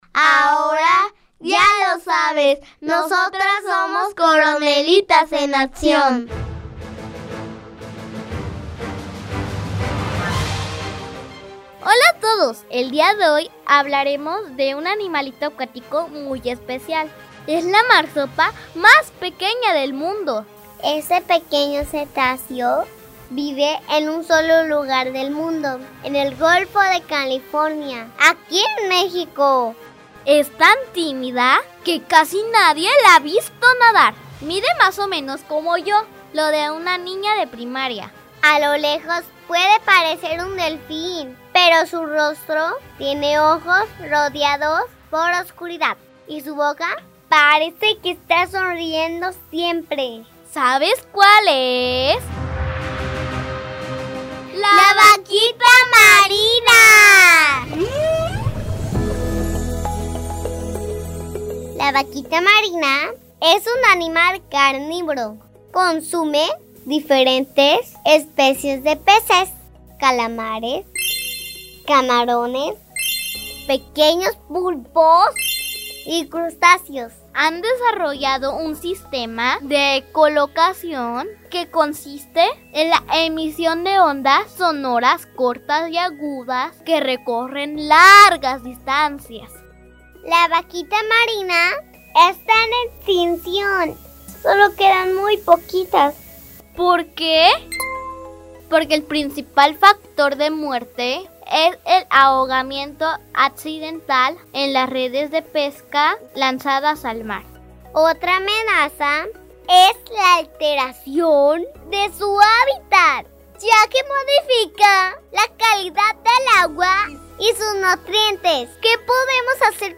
Son cápsulas radiofónicas que se encargan de hacer reflexionar acerca de los derechos de los animales, enfocándose en el conocimiento de especies en peligro de extinción o vulnerables, y haciendo referencia a la Declaración Universal de los Derechos de los Animales.